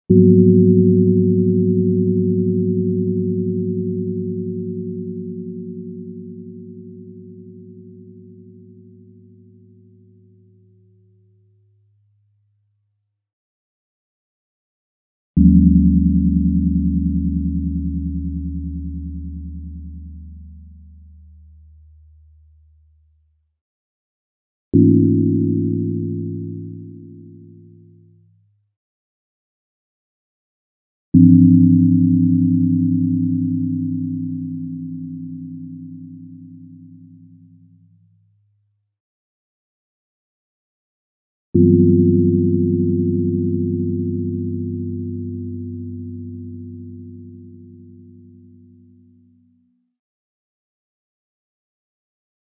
* A basic sine instrument implementation
We use this characteristic in the following piece to create an additive timbre where each partial has a slightly different envelope
and so the timbre changes over the duration of each note.
There is plenty of randomness in both the setting of the fundamental pitch, the overtone intervals, and overtone loudness - thus some gong sounds
The score creates 5 gong tones (chords) each with 8 partials (parts).
Gongs3.mp3